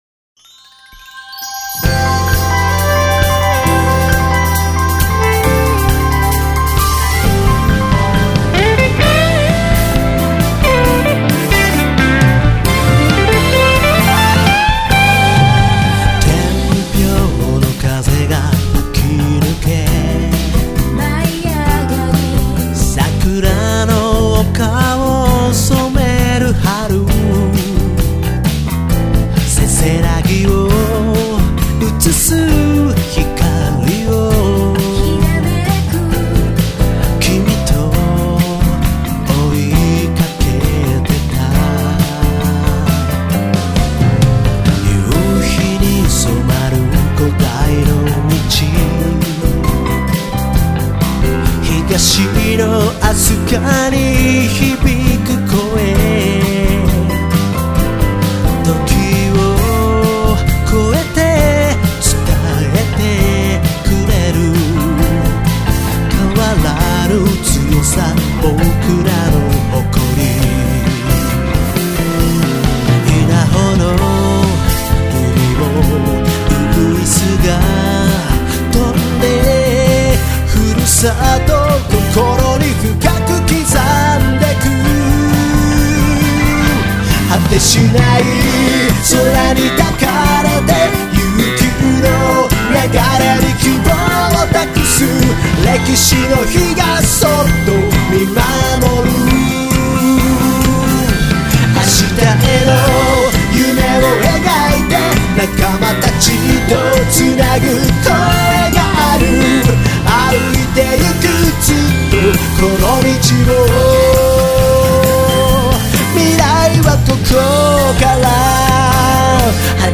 明るく、楽しく